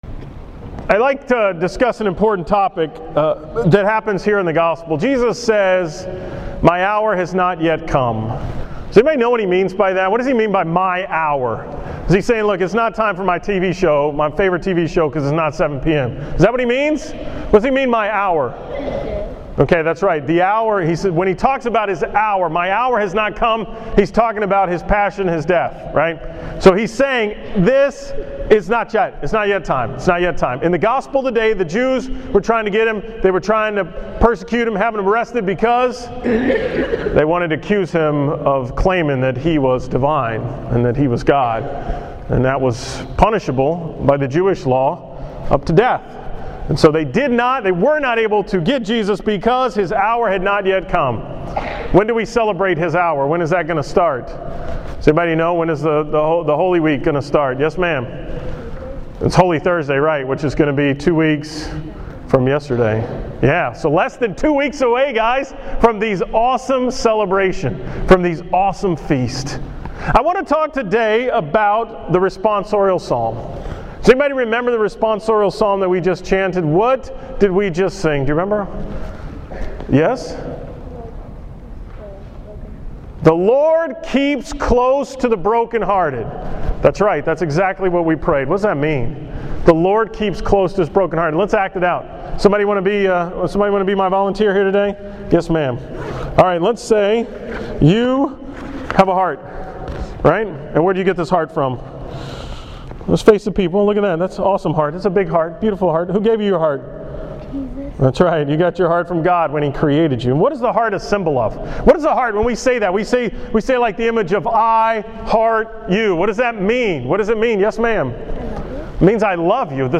From the School Mass on April 4, 2014
Category: 2014 Homilies, School Mass homilies